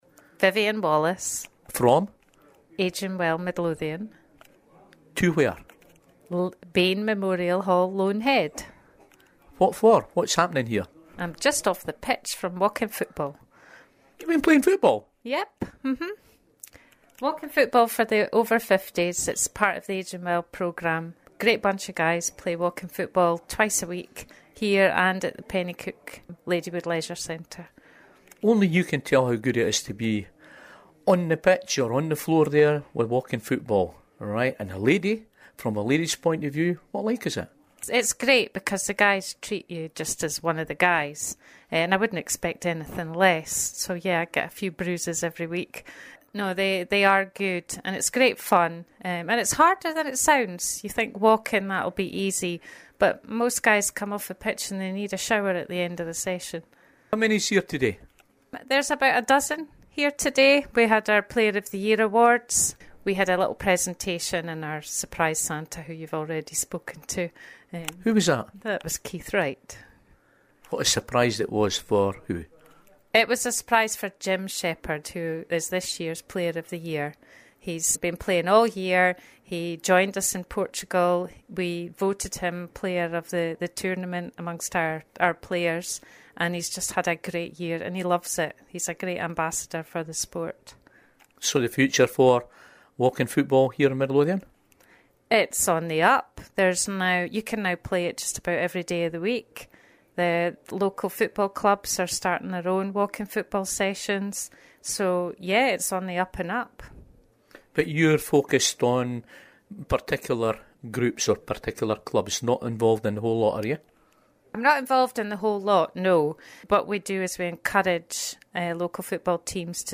At the Bayne Memorial Hall in Loanhead at the Player of the Year Award